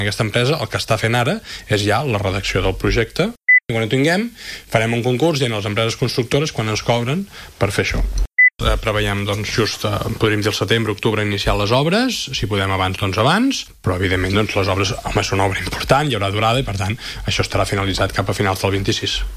Ho ha confirmat l’alcalde Marc Buch a l’entrevista a l’FM i +, on ha donat detalls del pressupost munipal per al 2025 que s’aprovarà aquesta tarda en sessió plenària.